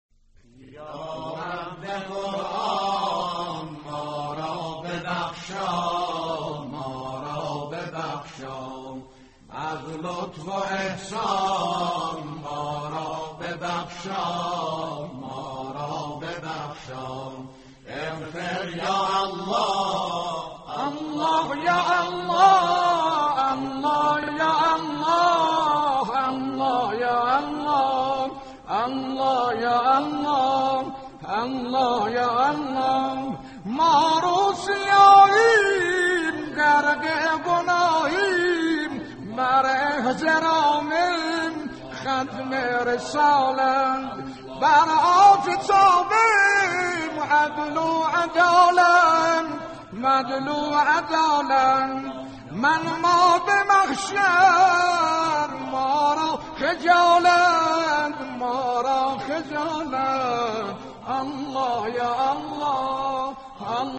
یا حق به قران ما را ببخشا ازلطف واحسان (باصدای موذن زاده اردبیلی) | انجمن گفتگوی دینی